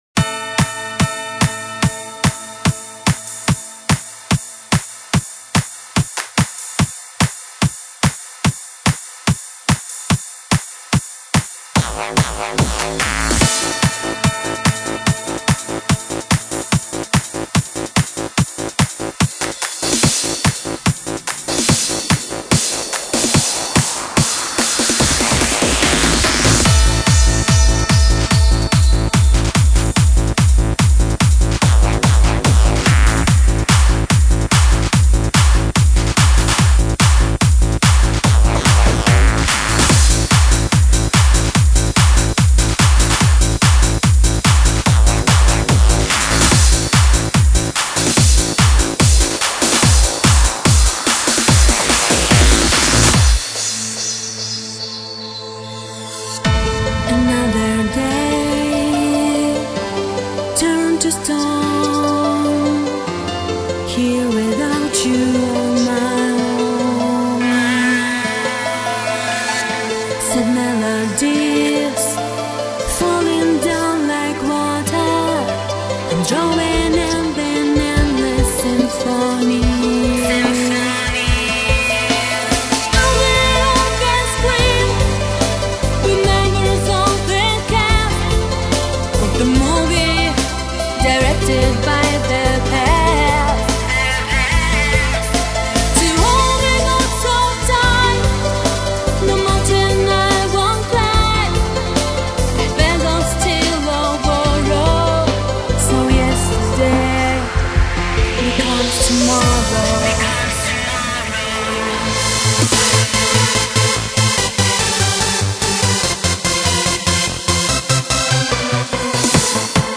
Genre: Dance | 1CD | VBR 320 kbps | ~180Mb MB